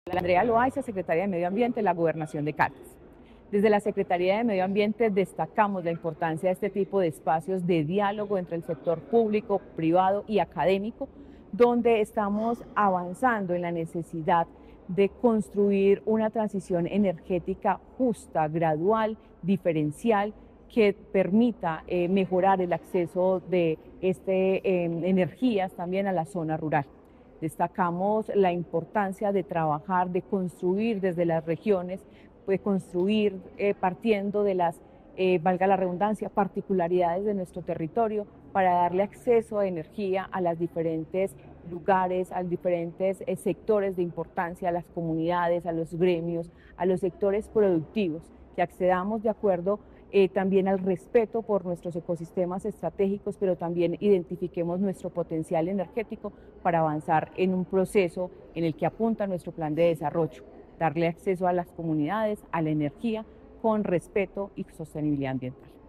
Paola Andrea Loaiza, secretaria de Medio Ambiente de Caldas.